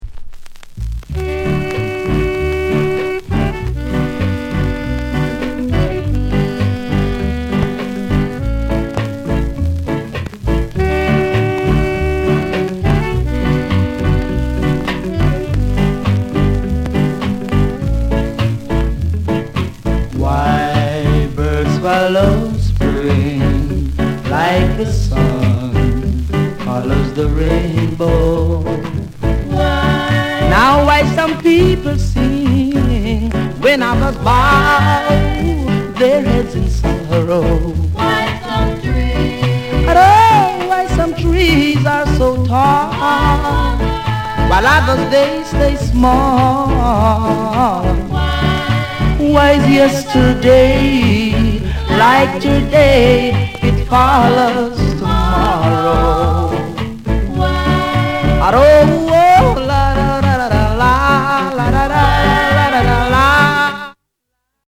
GREAT ROCKSTEADY